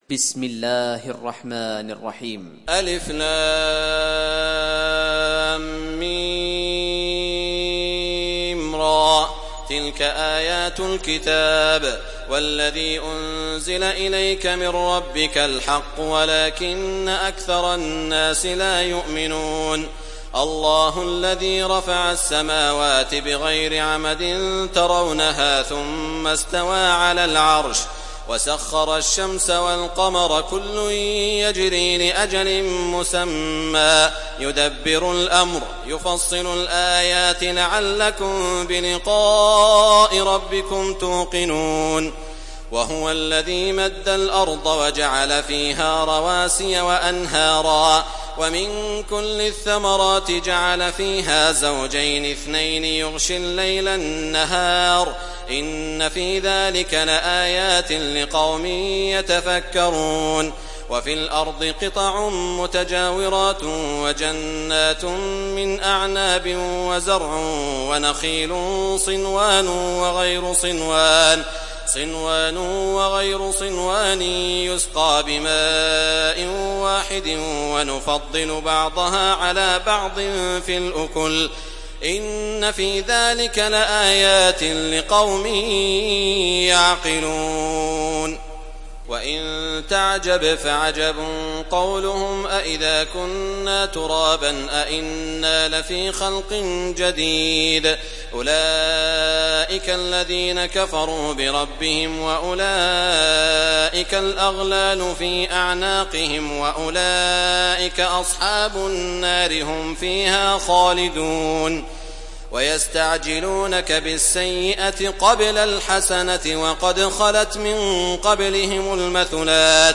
تحميل سورة الرعد mp3 بصوت سعود الشريم برواية حفص عن عاصم, تحميل استماع القرآن الكريم على الجوال mp3 كاملا بروابط مباشرة وسريعة